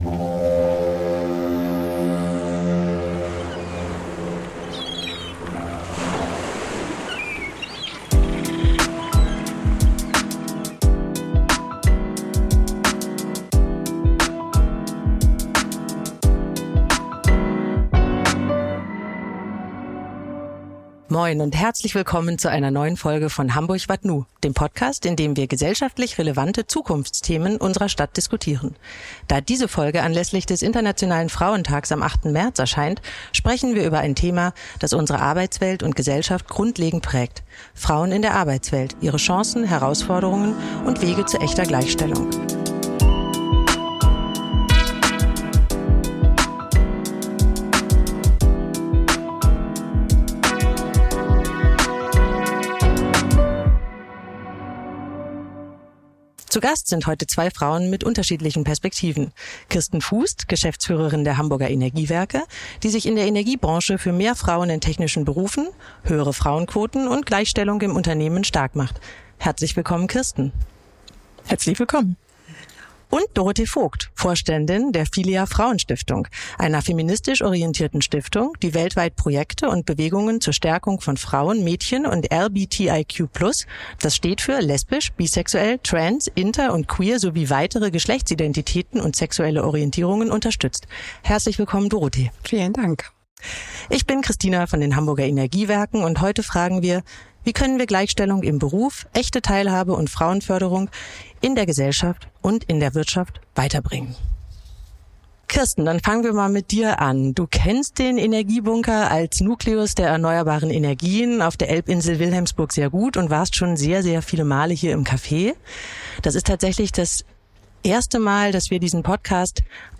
Ein Gespräch über Mut, Verantwortung und Gleichstellung als gemeinsame Aufgabe von Wirtschaft und Gesellschaft. Aufgenommen im Energiebunker Wilhelmsburg – mit Blick auf Hamburg und eine Arbeitswelt im Wandel.